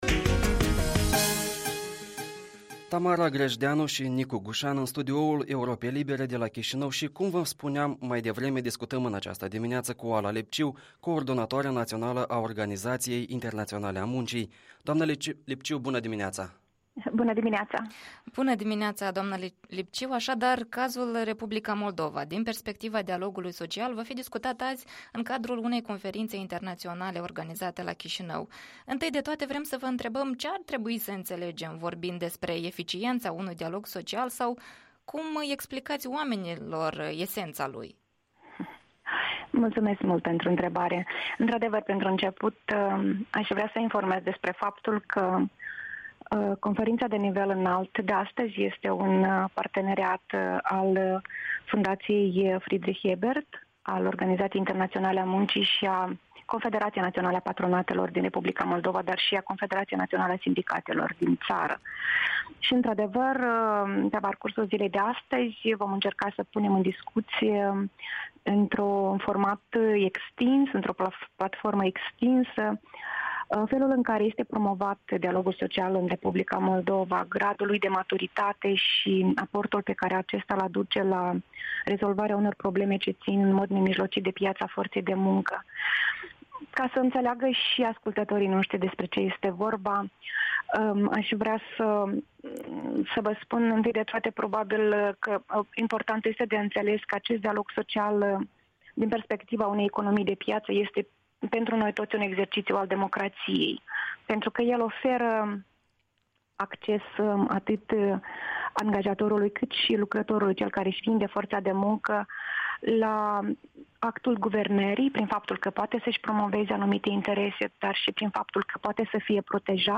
Interviul matinal la EL